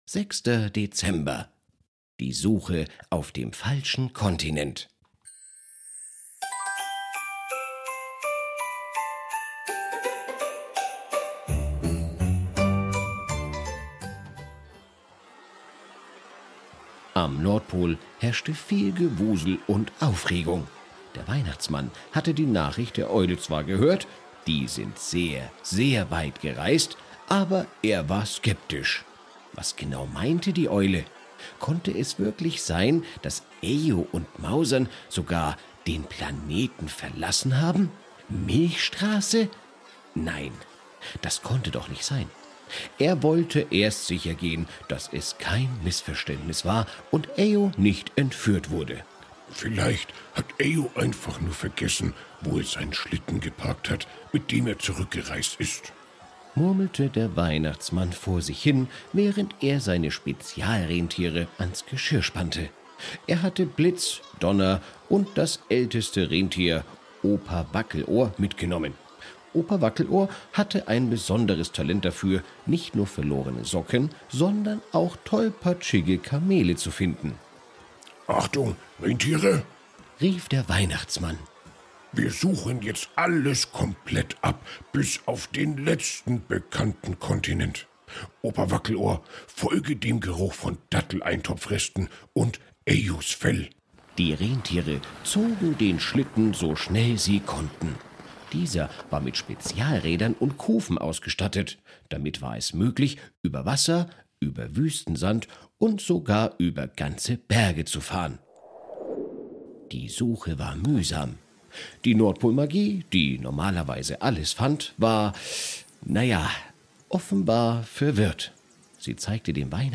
Ein Kinder Hörspiel Adventskalender
VoiceOver: